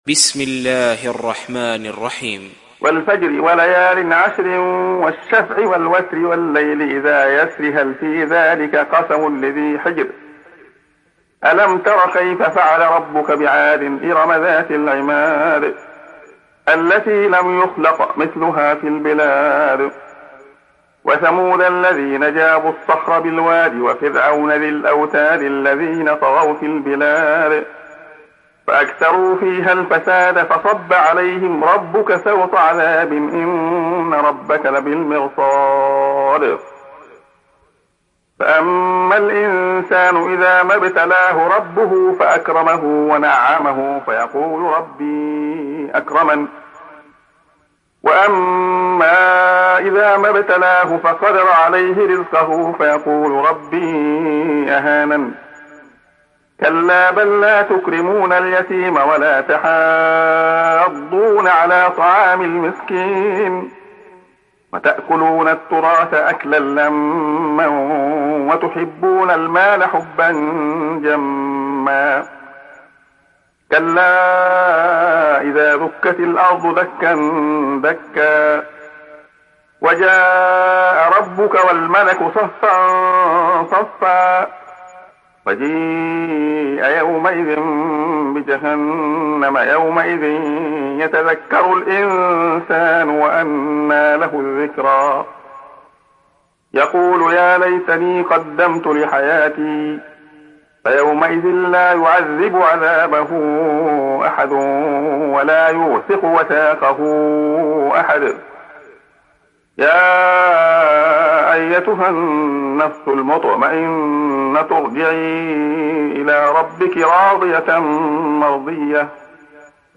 Sourate Al Fajr Télécharger mp3 Abdullah Khayyat Riwayat Hafs an Assim, Téléchargez le Coran et écoutez les liens directs complets mp3